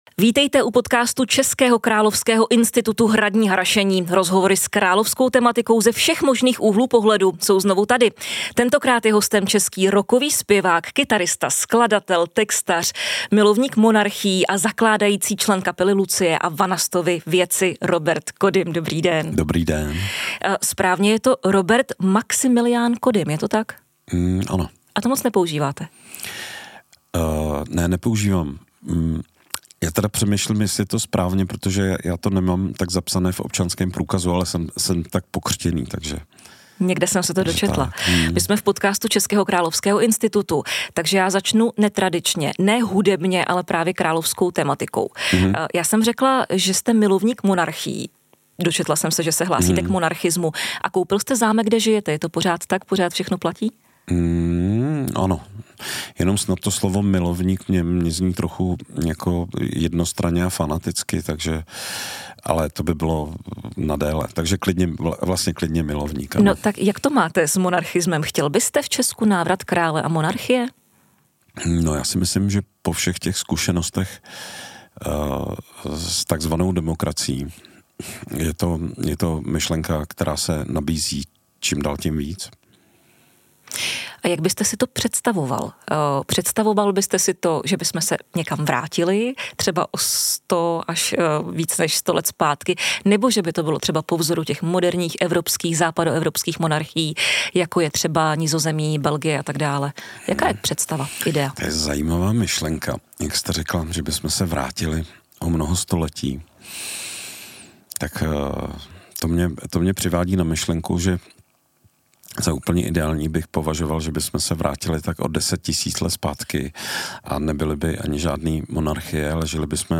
Hradní harašení - hostem rockový zpěvák a kytarista Robert Kodym